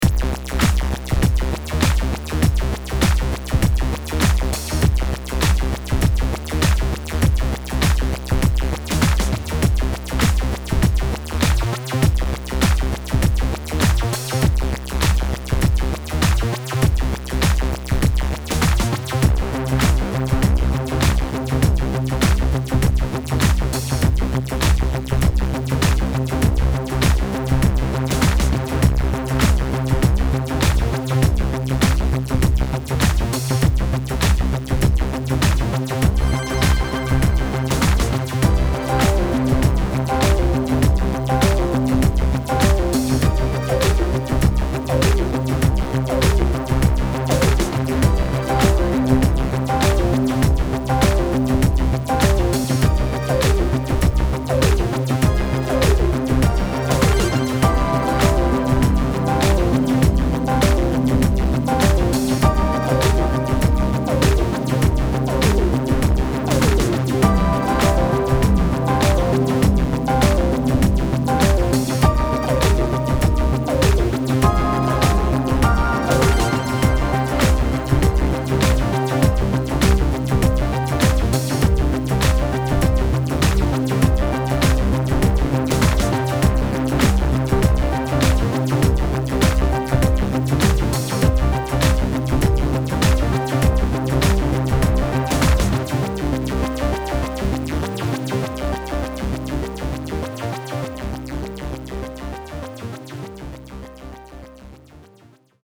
膨大なサウンドを秘めたシンプルなモノフォニック・シンセ